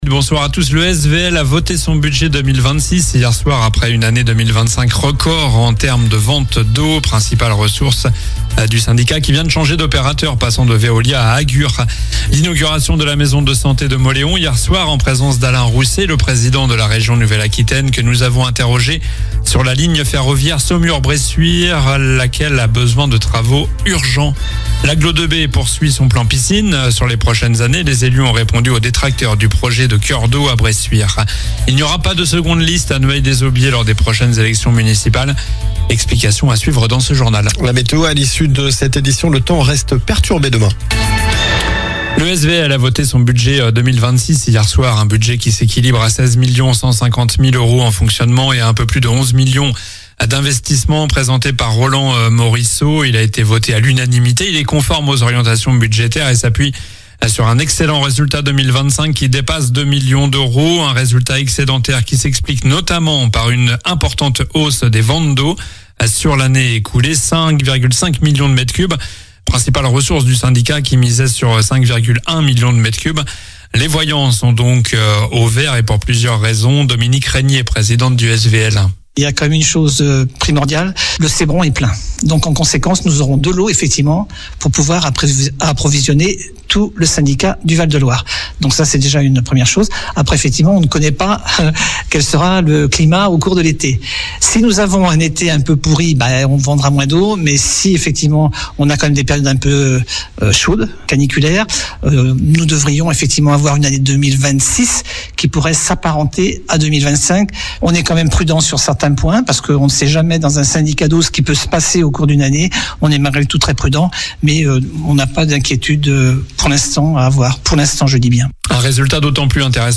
Journal du jeudi 05 février (soir)